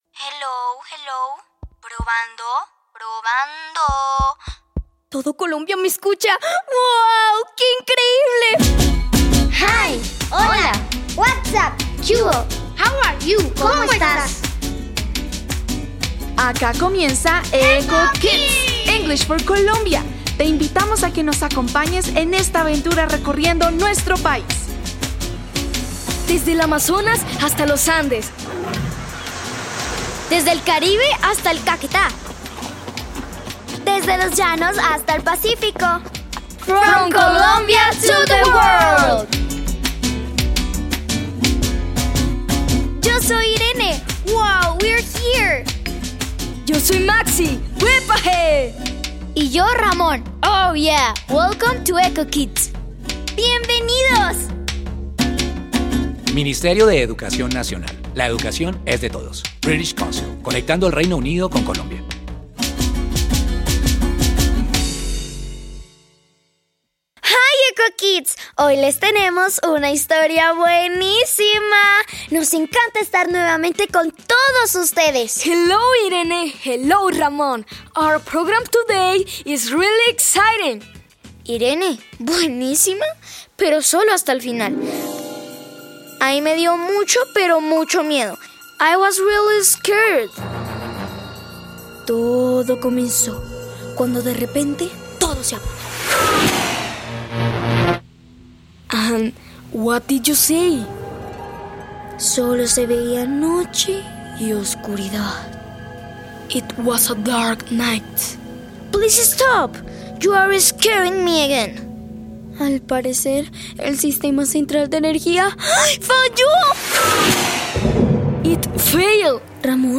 Introducción Este recurso ofrece un episodio radial de Eco Kids sobre una noche sin luz. Presenta situaciones cotidianas y expresiones en inglés que apoyan la comprensión auditiva.